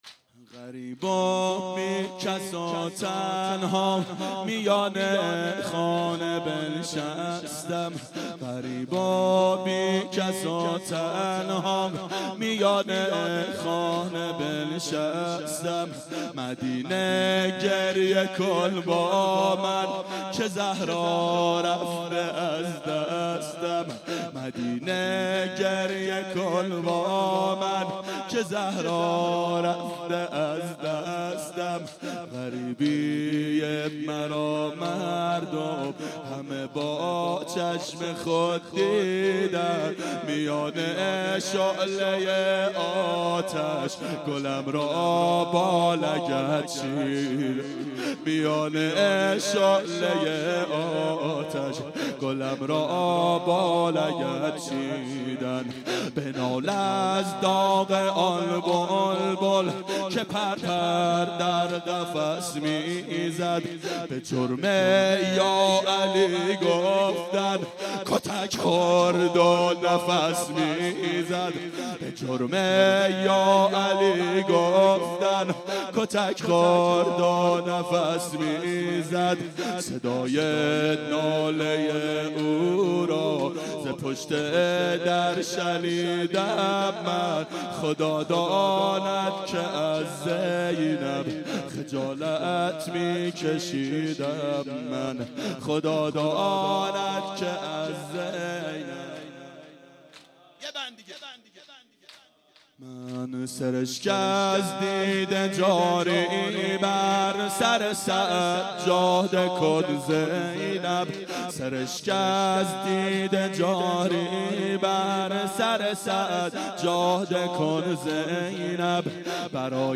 • دهه اول صفر سال 1392 هیئت شیفتگان حضرت رقیه سلام الله علیها